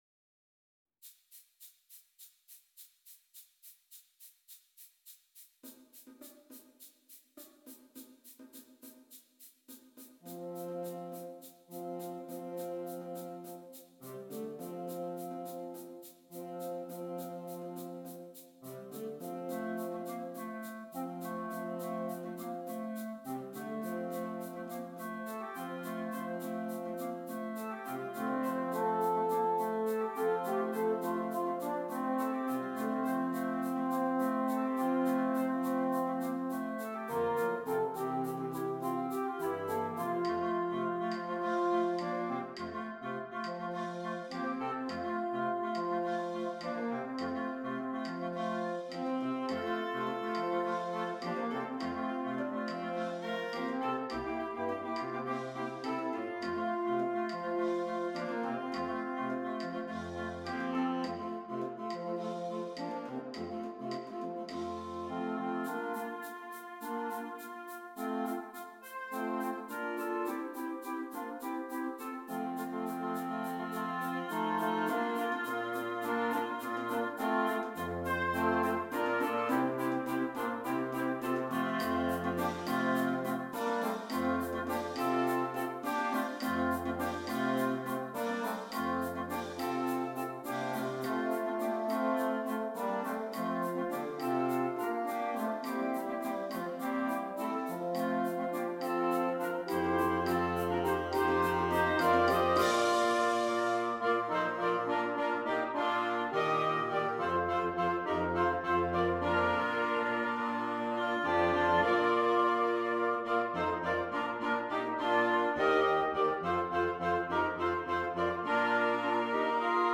Interchangeable Woodwind Ensemble
Traditional